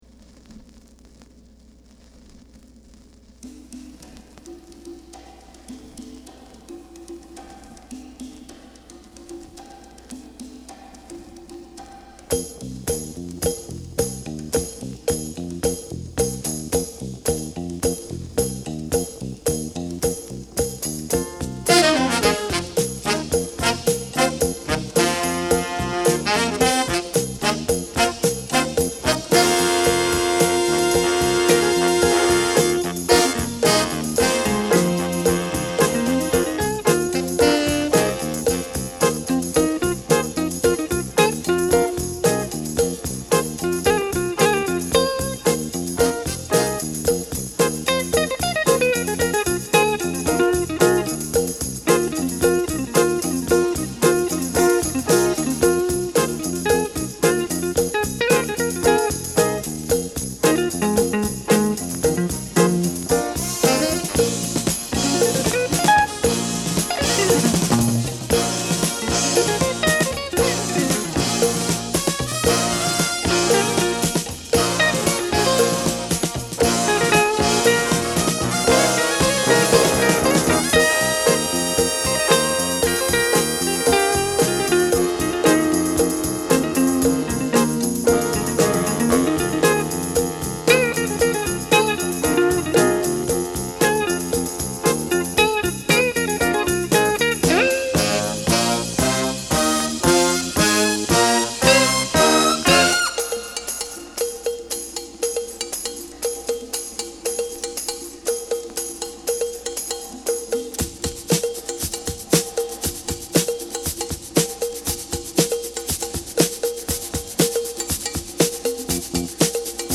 Genre: Funk / Soul
ディープなファンク・グルーヴが続く、ディガー向けの一枚。
ギターのカッティングが冒頭から腰に来る。